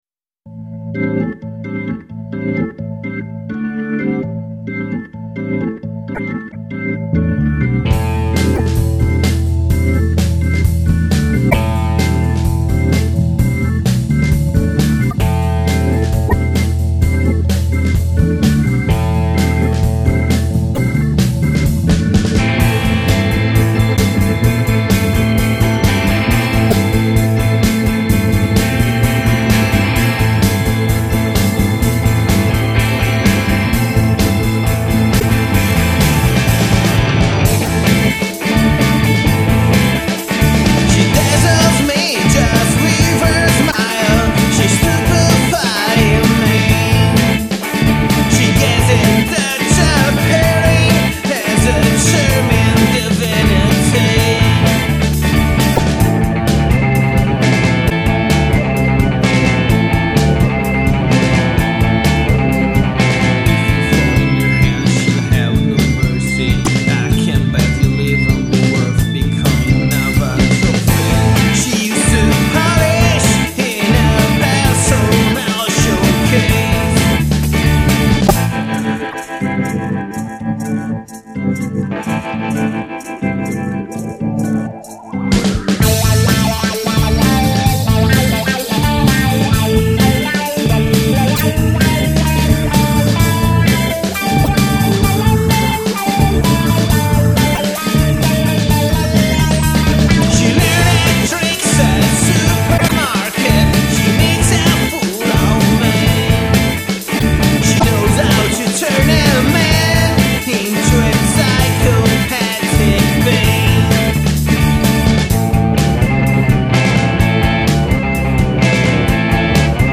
vocals, guitar, harp, backing vocals
Hammond organ, Farfisa organ, piano, keyboards
bass guitar, mini moog solos, noises, backing vocals
drums, percussions